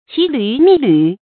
注音：ㄑㄧˊ ㄌㄩˊ ㄇㄧˋ ㄌㄩˊ
騎驢覓驢的讀法